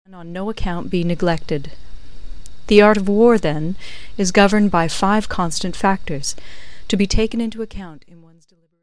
音频由北美的英语母语者录制，真人女声。发音标准、地道且专业。